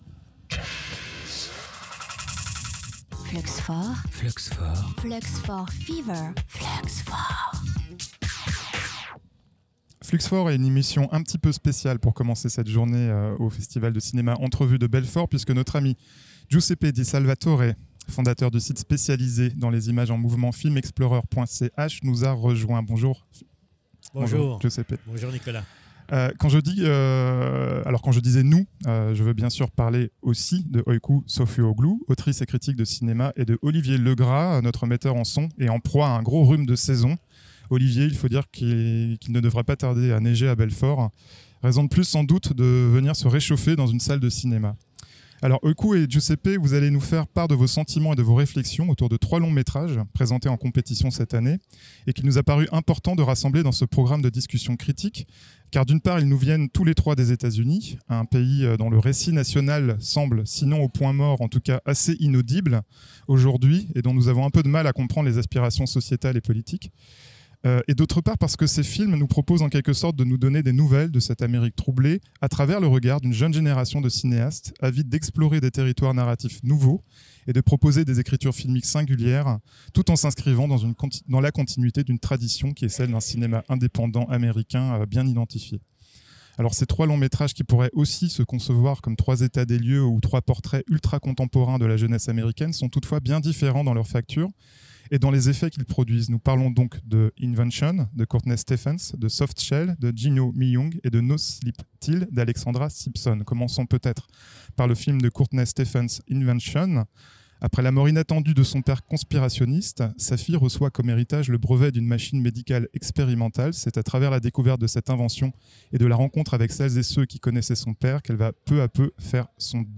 Entrevues 2024